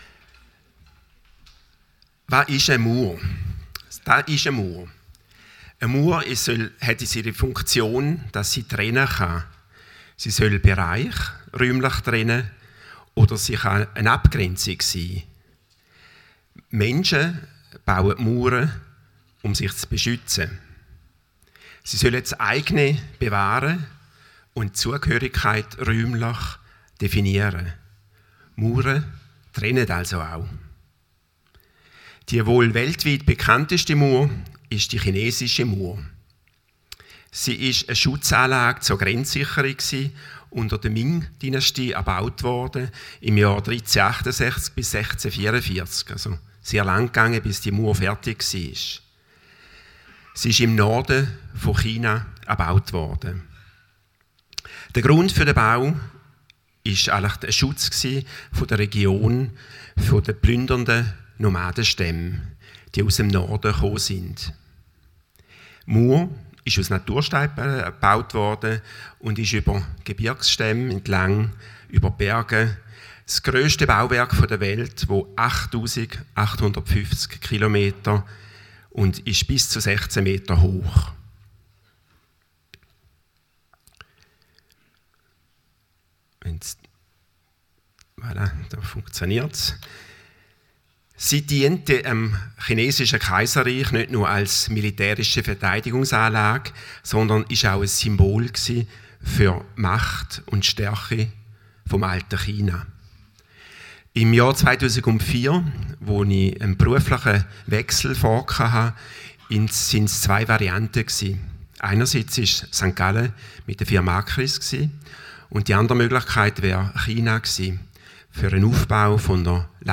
Predigt 2. März 2025